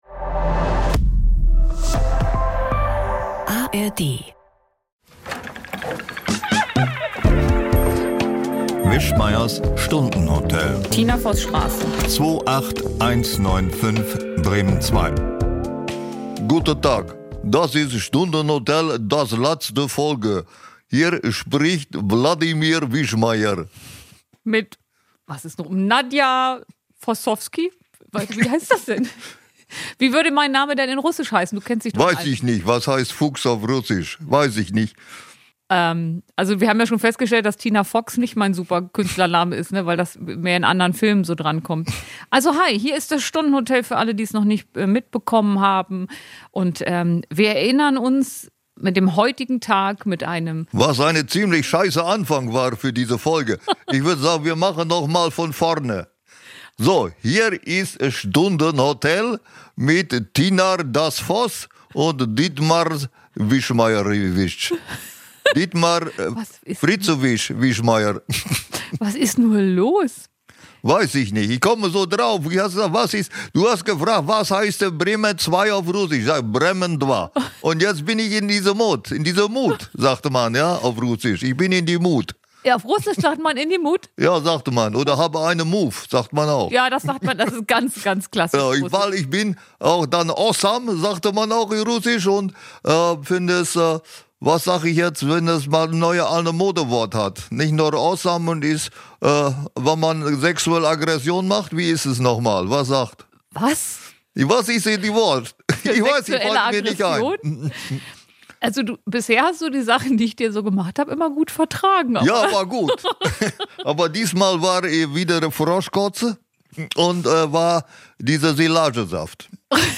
Mach's mir von vorne – Der Jahresausblick 2026 live aus Bremen